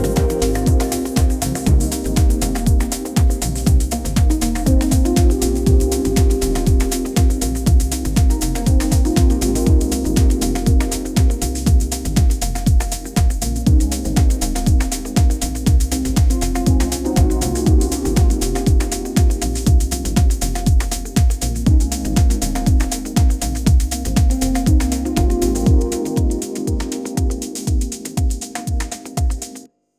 audio-to-audio balearic-house music-generation